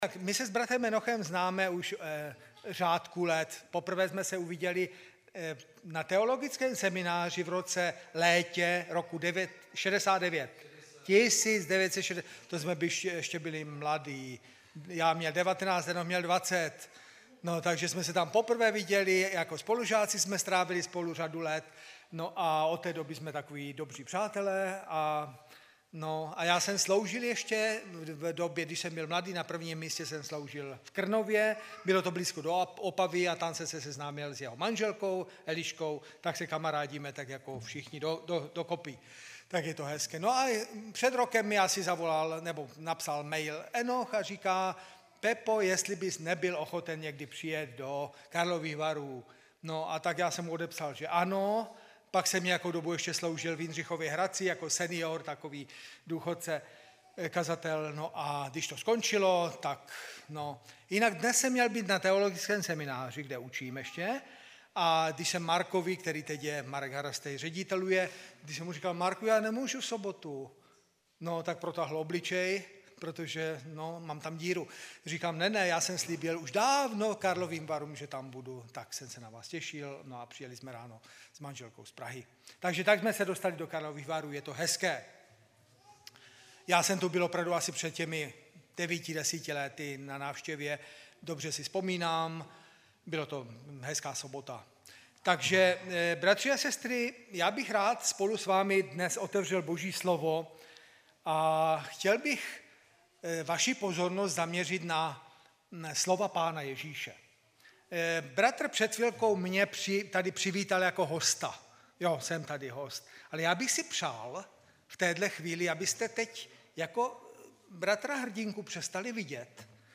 16.10.2016 v 11:22 do rubriky Kázání .